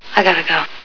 The Fly Movie Sound Bites